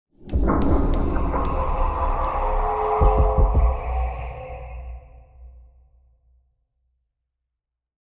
Minecraft Version Minecraft Version snapshot Latest Release | Latest Snapshot snapshot / assets / minecraft / sounds / ambient / cave / cave23.ogg Compare With Compare With Latest Release | Latest Snapshot
cave23.ogg